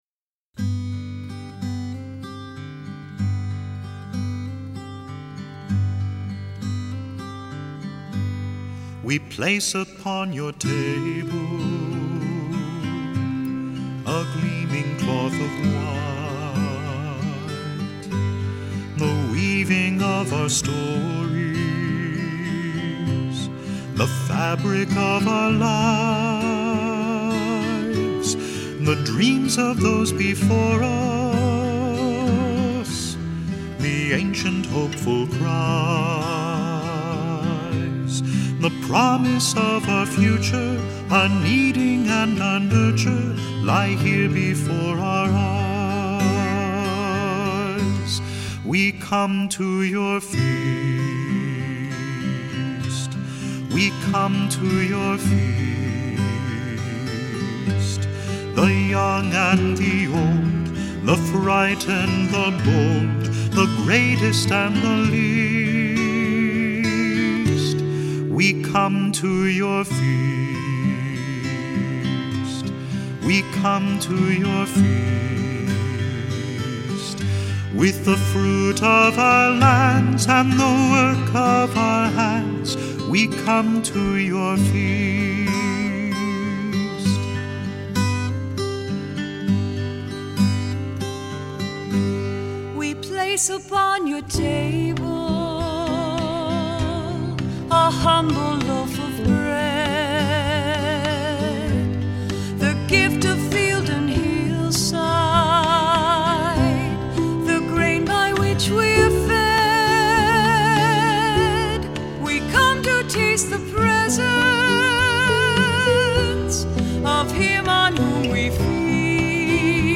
Accompaniment:      Keyboard
Music Category:      Christian
Flute and oboe parts are optional.